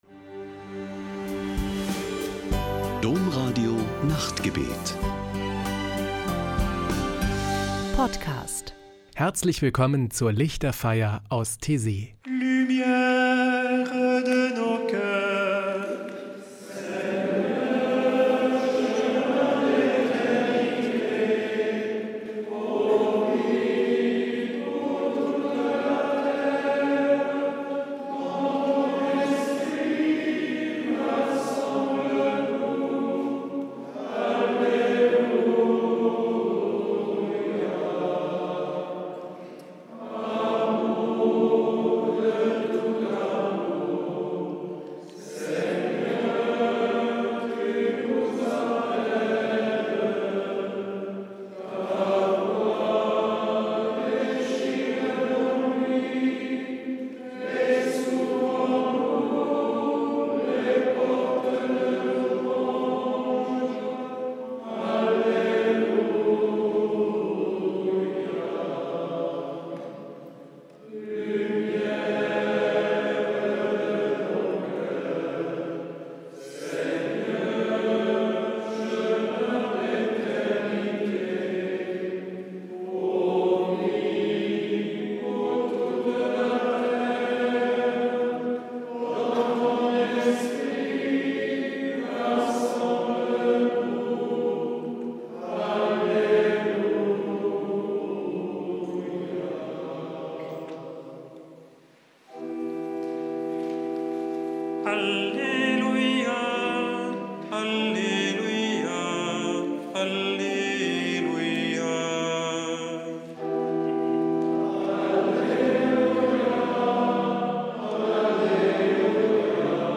Ein Höhepunkt jede Woche ist am Samstagabend die Lichterfeier mit meditativen Gesängen und Gebeten.